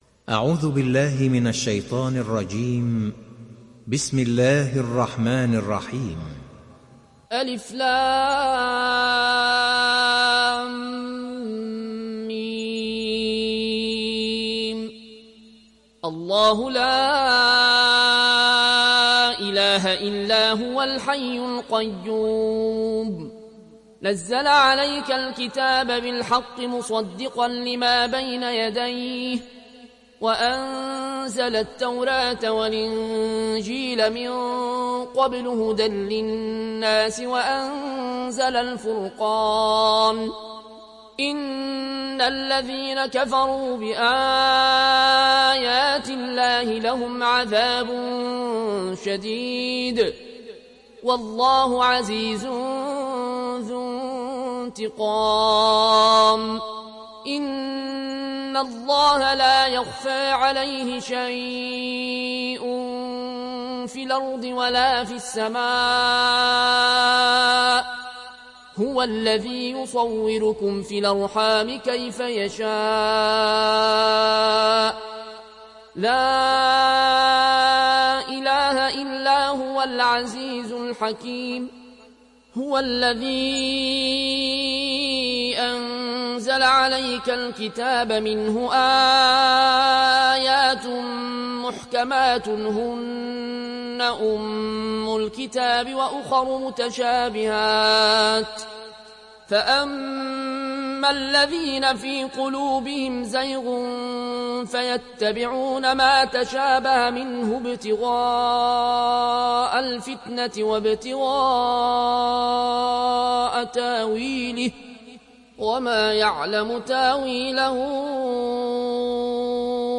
تحميل سورة آل عمران mp3 العيون الكوشي (رواية ورش)